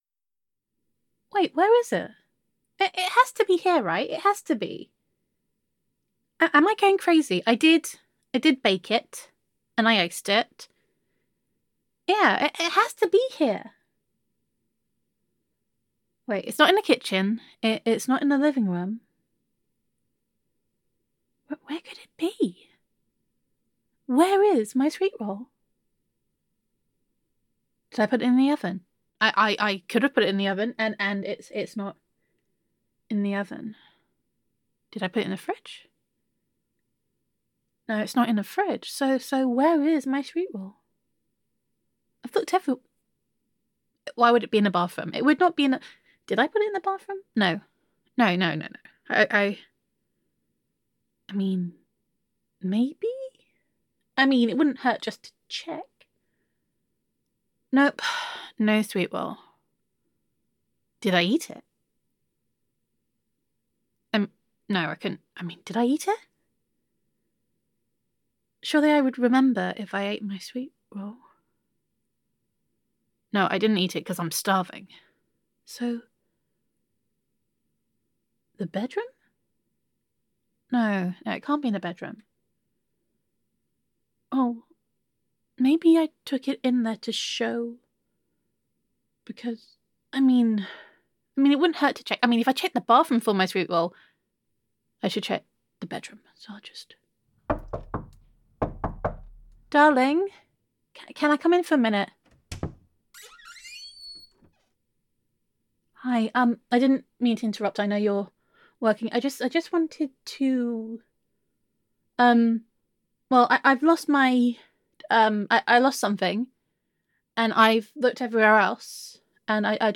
[F4A] You Stole My Sweetroll! [Quarantine Baking][Sneak 100][Speech 100][Stolen Books][Subtle Skyrim References][Girlfriend Roleplay][Gender Neutral][The Case of the Missing Sweetroll]